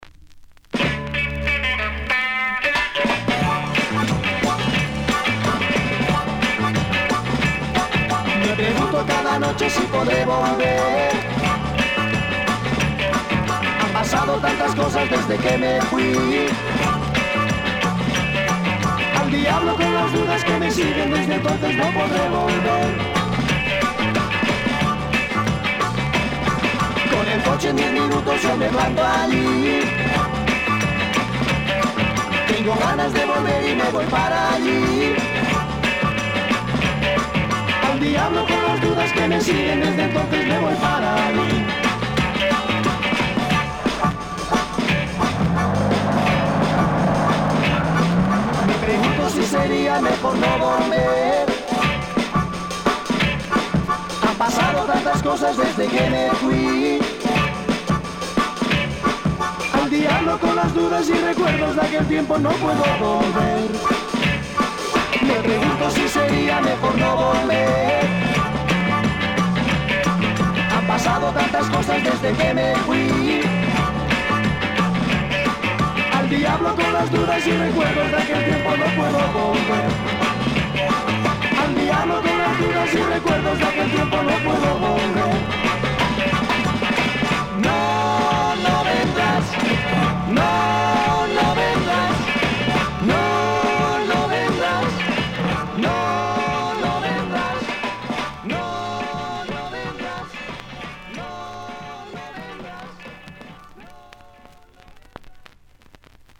Published February 6, 2011 Garage/Rock Leave a Comment